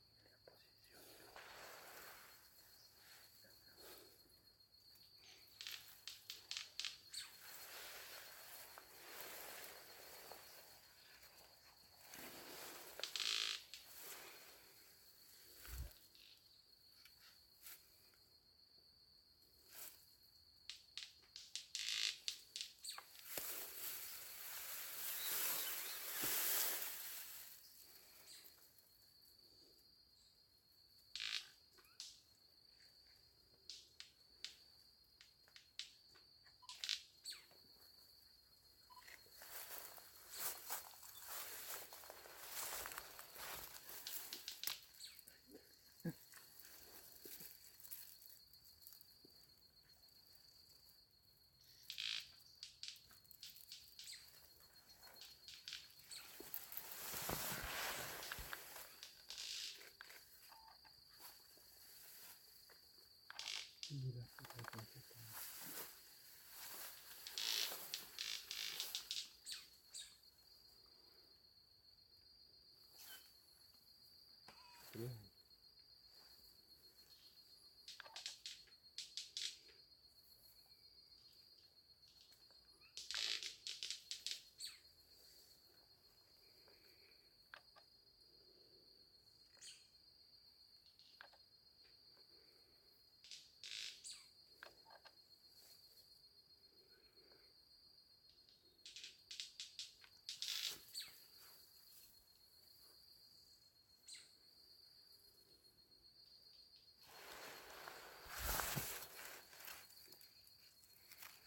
White-bearded Manakin (Manacus manacus)
Country: Paraguay
Location or protected area: Mbaracayú--Jejuimi
Condition: Wild
Certainty: Observed, Recorded vocal
bailarin-blanco.mp3